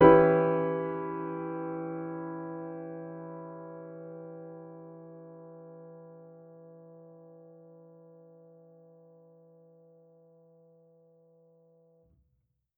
Index of /musicradar/jazz-keys-samples/Chord Hits/Acoustic Piano 1
JK_AcPiano1_Chord-Em6.wav